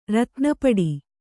♪ ratna paḍi